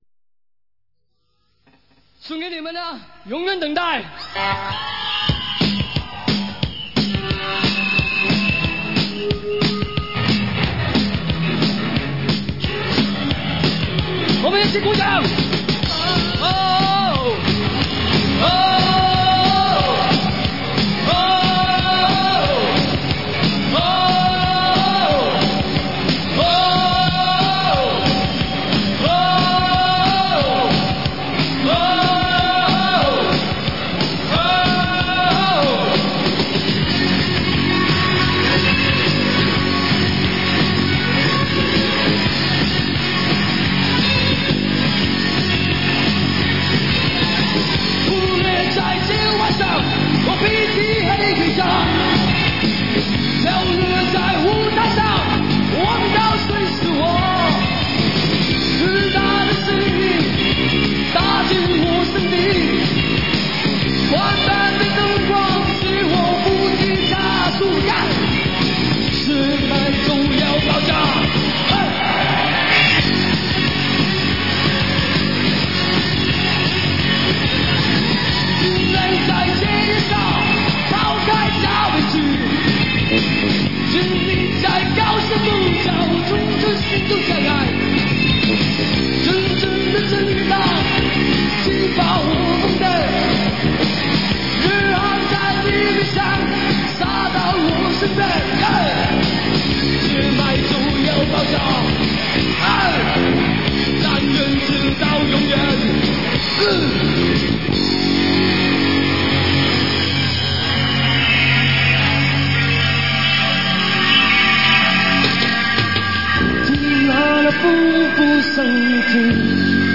1986年【台北演唱会】